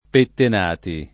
[ petten # ti ]